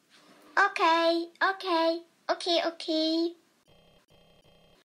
Ok Ok…Okay Okay… (girl) sound effect
Okay sound effect Women Saying Okay sound effect
Thể loại: Âm thanh meme Việt Nam
ok-ok-okay-okay-girl-sound-effect-www_tiengdong_com.mp3